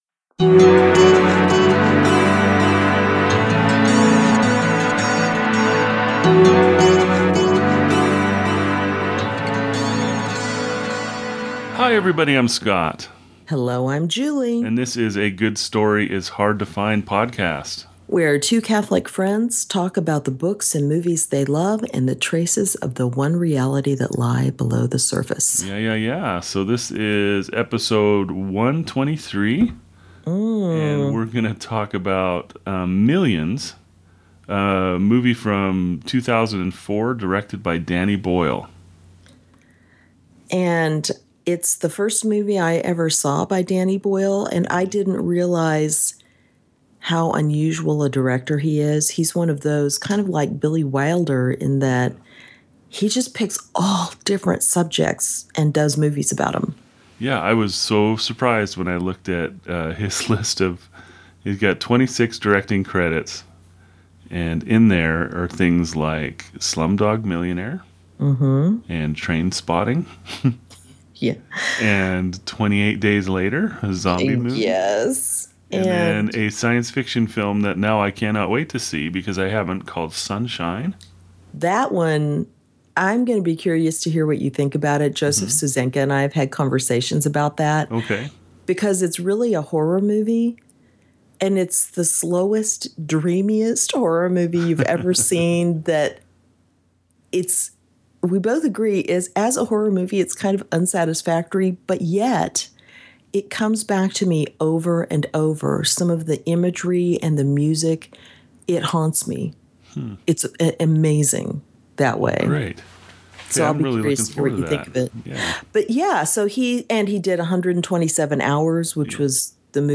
Two Catholics talking about books, movies and traces of "the One Reality" they find below the surface.